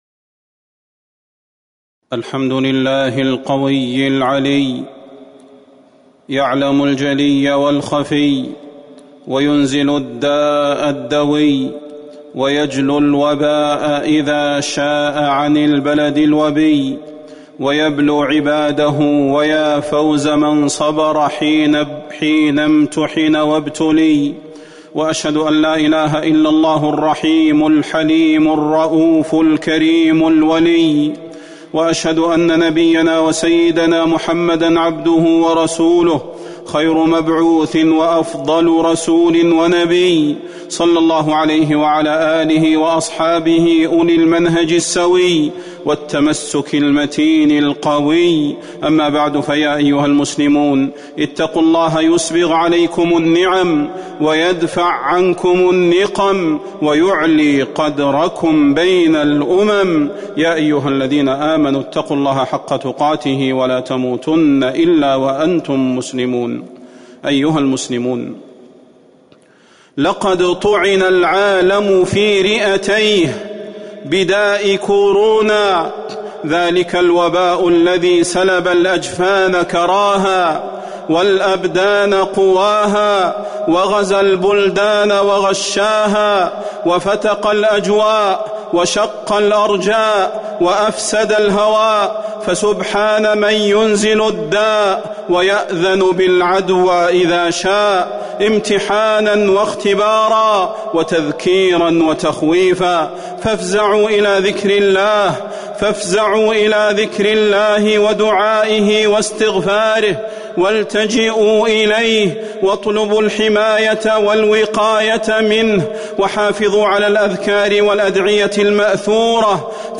فضيلة الشيخ د. صلاح بن محمد البدير
تاريخ النشر ١١ رجب ١٤٤١ هـ المكان: المسجد النبوي الشيخ: فضيلة الشيخ د. صلاح بن محمد البدير فضيلة الشيخ د. صلاح بن محمد البدير الوقاية من وباء كورونا